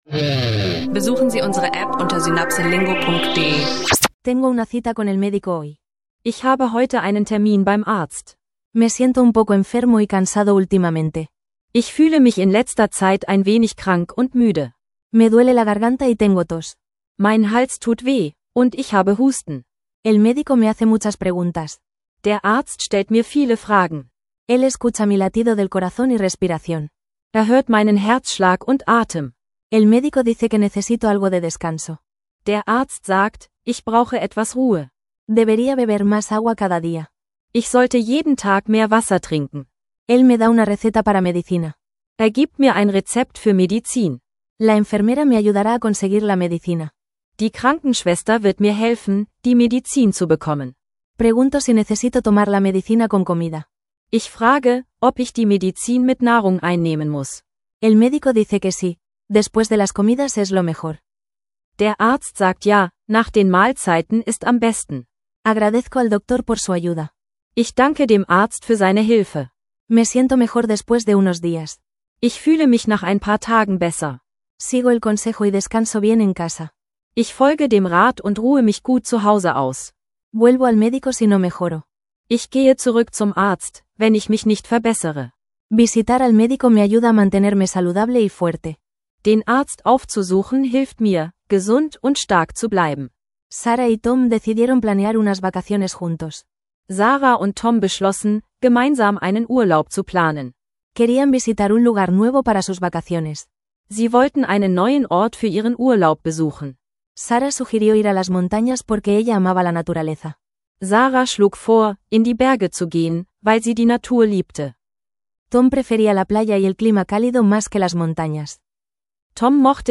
In dieser Episode des SynapseLingo Podcasts lernen Sie Spanisch mit realistischen Dialogen zum Arztbesuch, zur Urlaubsplanung und zu den Grundlagen der Finanzmärkte. Ideal für Spanisch Anfänger, die Spanisch lernen mit Spaß und interaktiven Übungen suchen.